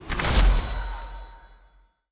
snd_ui_phase_finish.wav